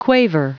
Prononciation du mot quaver en anglais (fichier audio)
Prononciation du mot : quaver